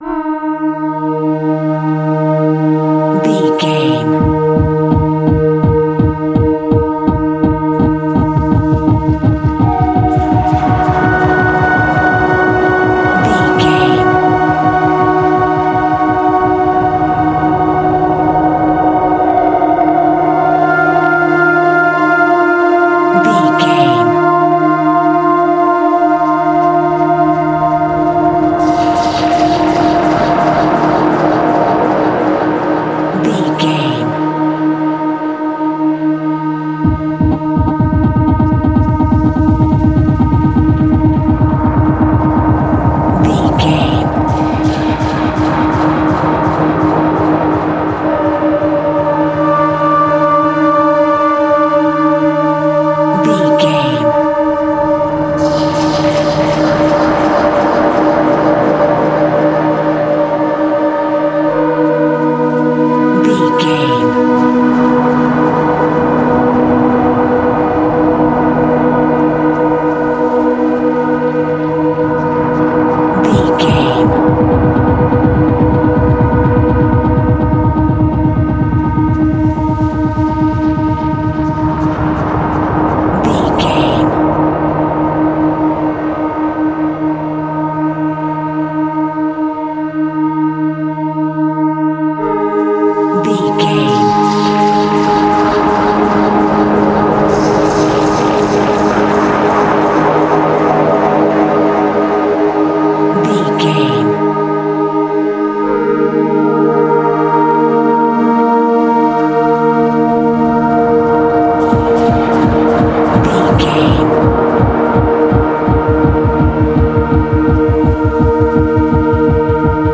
Horror Industrial Music.
Aeolian/Minor
E♭
Slow
tension
ominous
dark
eerie
strings
synthesiser
pads